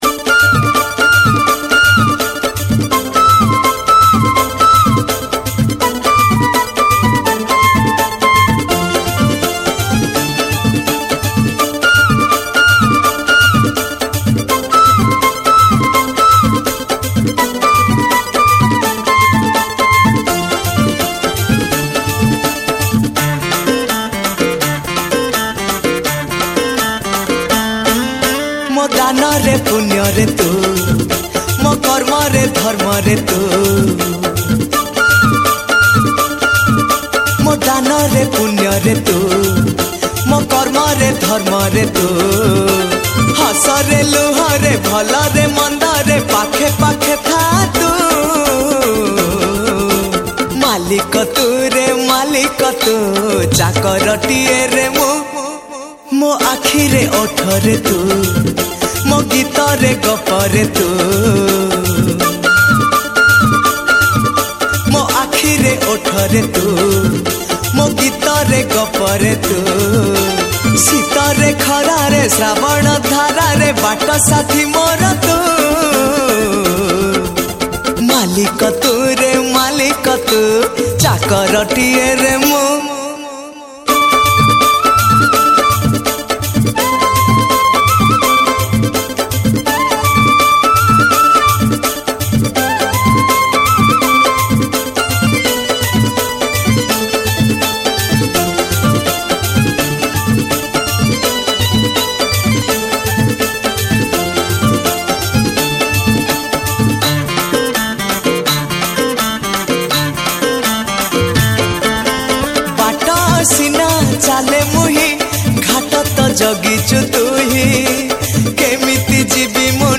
Odia Bhajan Song